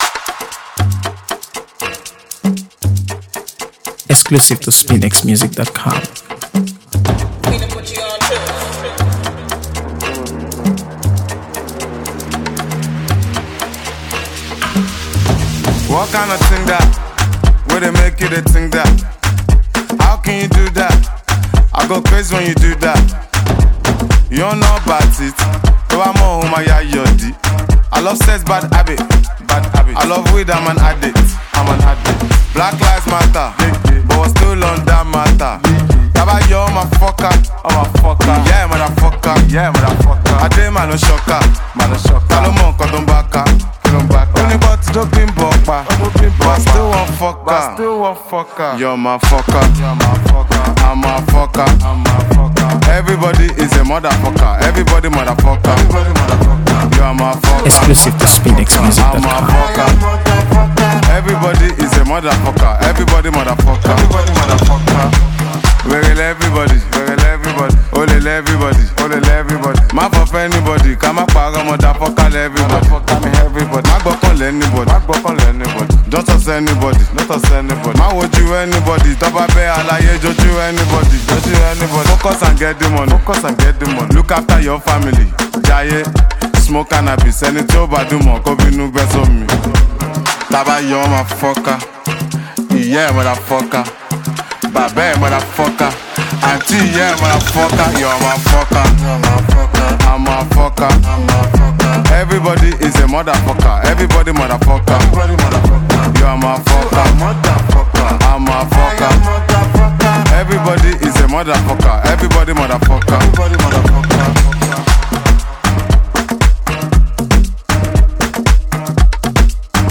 AfroBeats | AfroBeats songs
If you enjoy energetic, no-holds-barred afro-street music